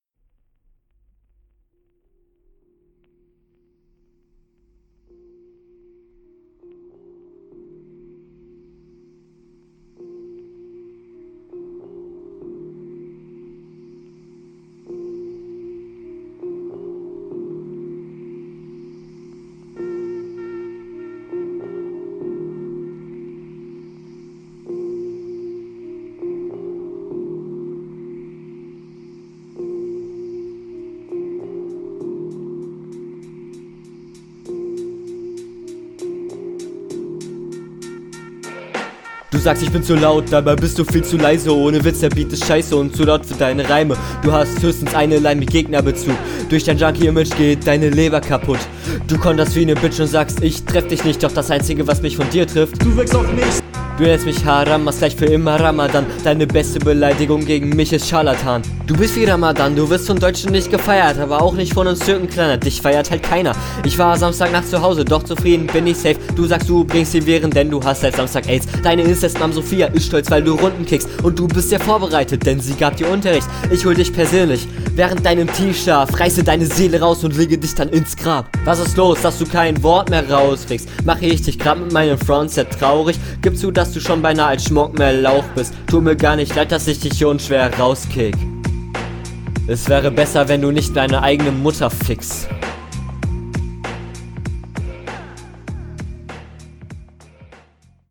Wie erwartet klingt das bei dir noch lange nicht so locker wie bei deinem Gegner.